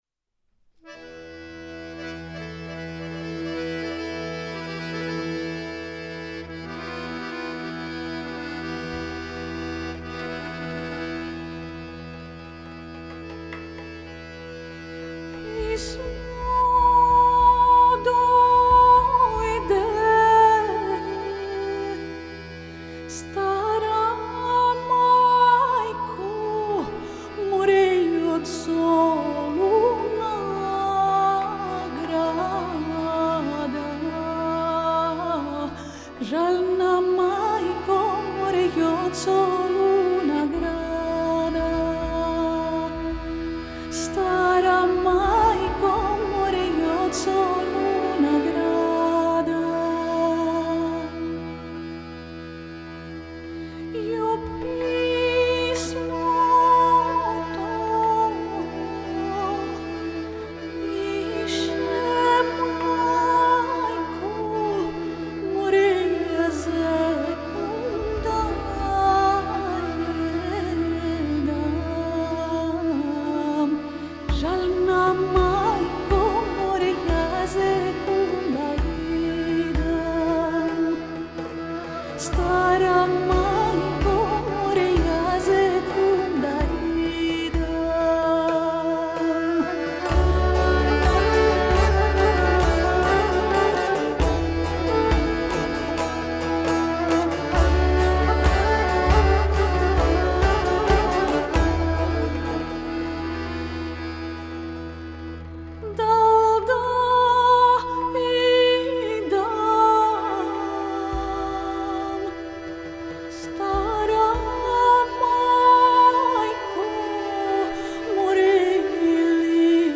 Жанр: Alternatif.